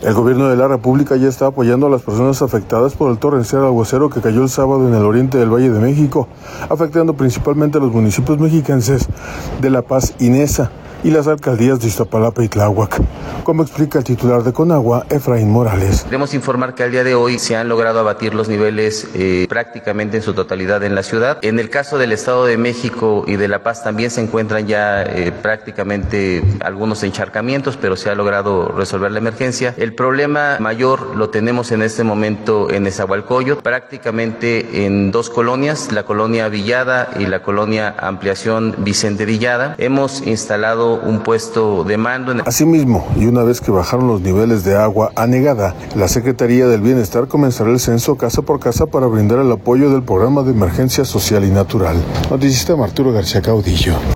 El Gobierno de la República, ya está apoyando a las personas afectadas por el torrencial aguacero que cayó el sábado en el oriente del Valle de México, afectando principalmente los municipios mexiquenses de la Paz y Neza; y las alcaldías de Iztapalapa y Tláhuac, como explica el titular de Conagua, Efraín Morales.